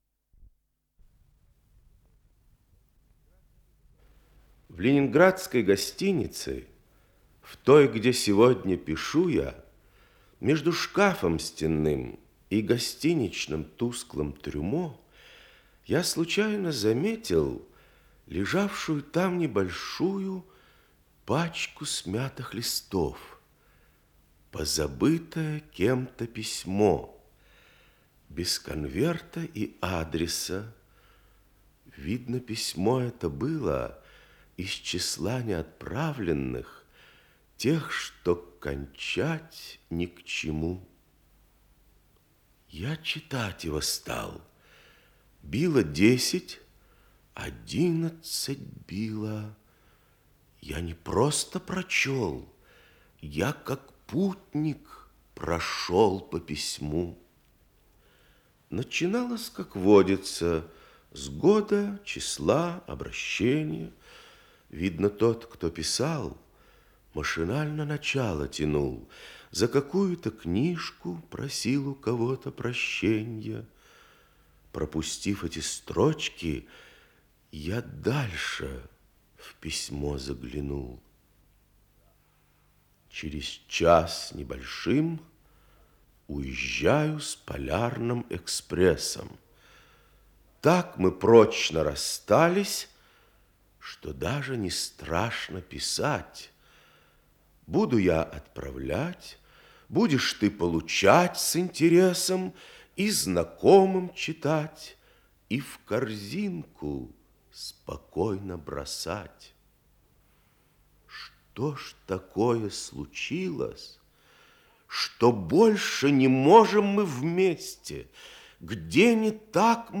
чтение